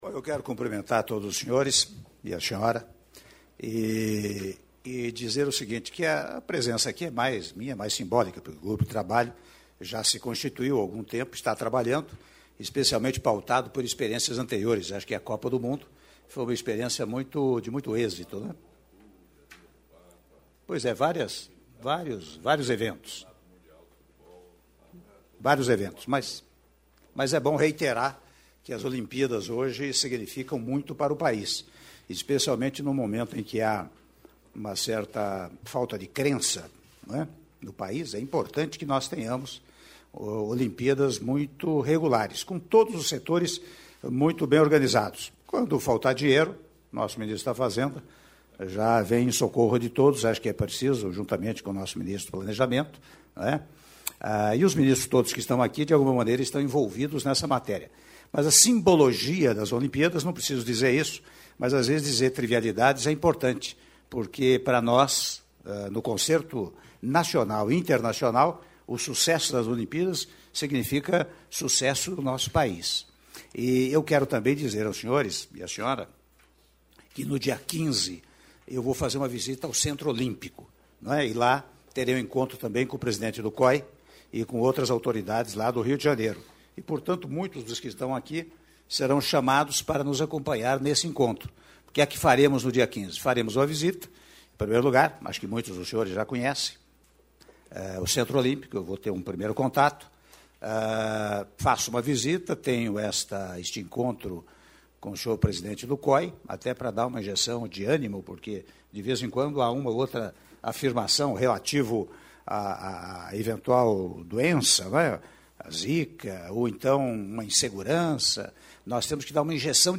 Áudio da fala do Presidente da República interino, Michel Temer, na reunião sobre as Olimpíadas Rio 2016 - (03min08s) - Brasília/DF